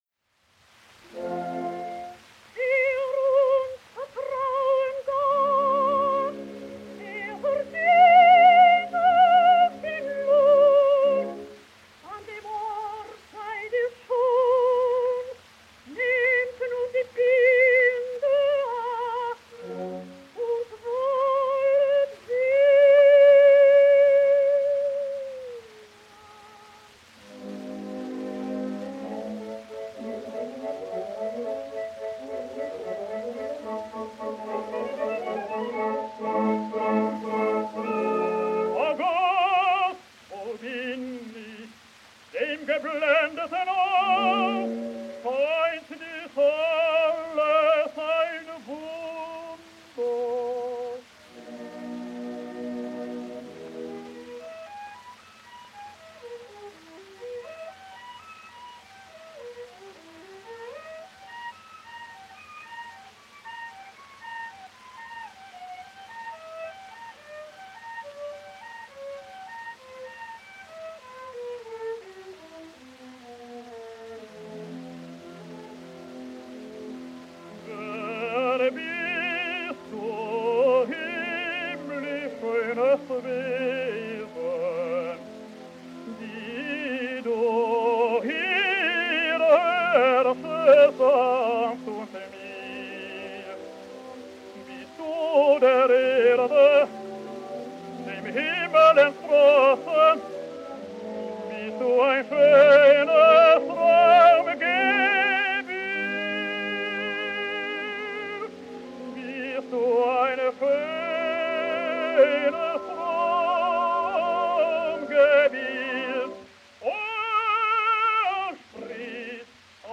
Parlare di esecuzione padigmatica e del duetto beaute divine interpretato e cantato da Frieda Hempel ed Hermann Jadlovker è sinonimo. Mai nessuna coppia tenore-soprano è stata più uguale per raffinata completezza tecnica, per modo di porgere la frase, per aderenza al clima ed alla poetica del grand-opera. Basta ascoltare ed ammirare il rispetto per le indicazioni del testo musicale, la varietà ed il gusto degli inserimenti il tutto per ampliare ed esemplificare il carattere dei personaggi languido e sensuale Margherita, sognante e casto, ma nel contempo eroico e nobile Raoul
Huguenots-Hempel-e-Jadlowker.mp3